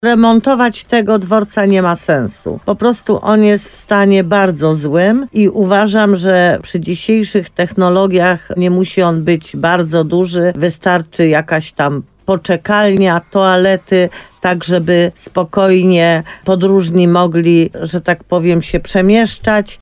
– Trwają już pierwsze rozmowy w tej sprawie z PKP – mówiła w programie Słowo za Słowo na antenie RDN Nowy Sącz burmistrz Limanowej, Jolanta Juszkiewicz. Teraz gdy modernizacja linii 104 na terenie miasta powoli zbliża się do końca, samorząd zrobił przegląd infrastruktury, z której będą korzystać pasażerowie i mieszkańcy.
Rozmowa z Jolantą Juszkiewicz: Tagi: Słowo za Słowo Limanowa dworzec PKP linia kolejowa linia kolejowa 104 Jolanta Juszkiewicz